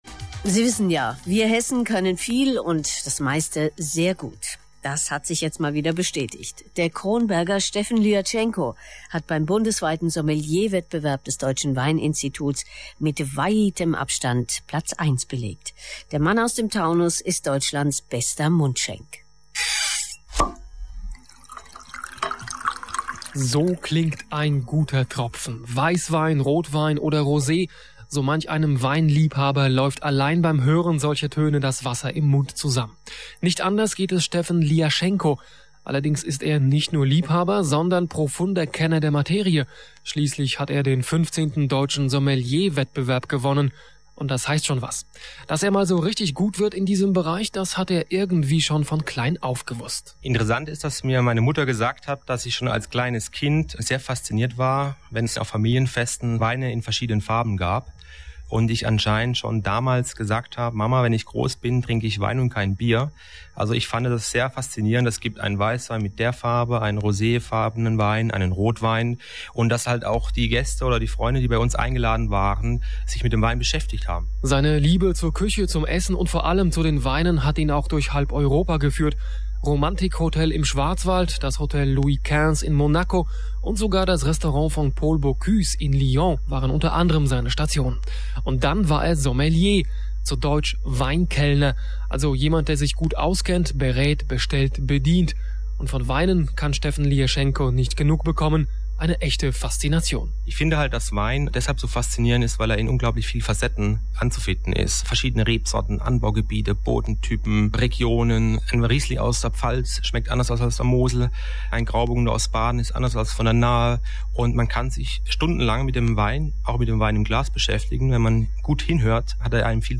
Radiobericht HR 1 zum Download (mp3 / 2,7 mb)